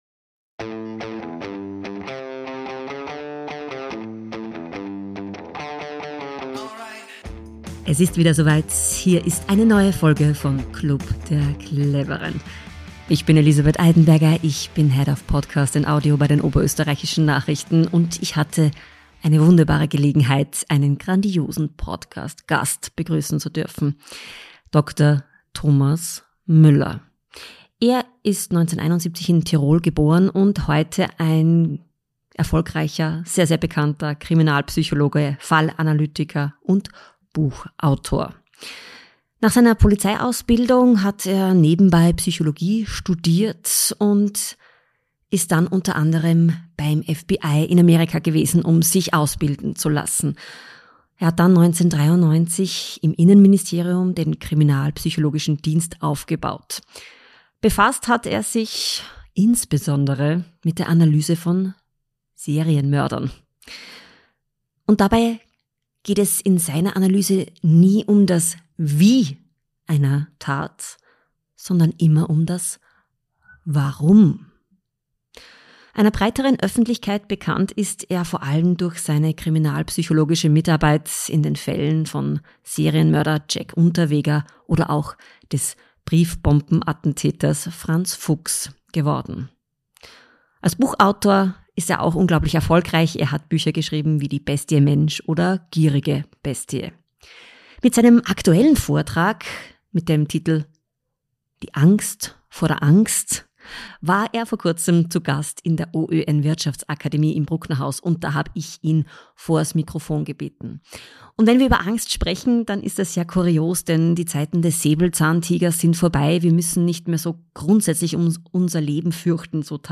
Kriminalpsychologe Dr. Thomas Müller im Podcast-Interview: Wie muss jemand gestrickt sein, der sich mit Serienmördern beschäftigt? Wie gehe ich mit meinen Ängsten um? Und was passiert, wenn die Angst überhand nimmt?